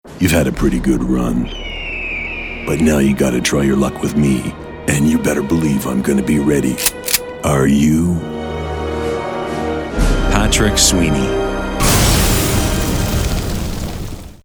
Sprechprobe: Sonstiges (Muttersprache):
Warm, conversational, friendly voice, but versatile enough to deliver corporate, authoritative, announcer like reads.